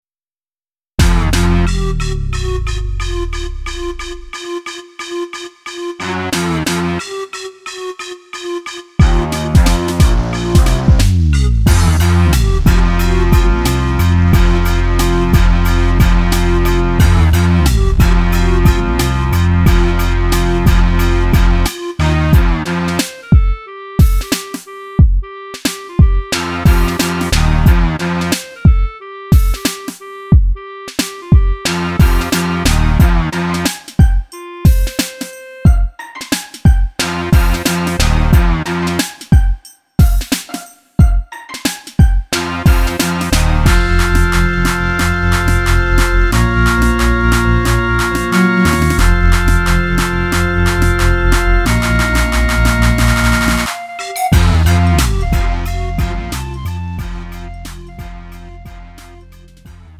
음정 -1키 3:33
장르 가요 구분